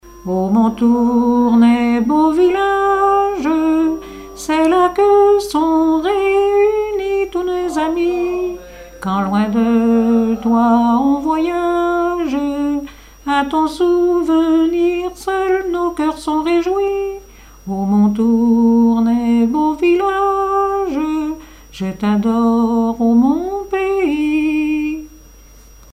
le refrain seul
Genre strophique
Répertoire de chansons populaires et traditionnelles
Pièce musicale inédite